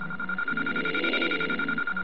Beacon2.wav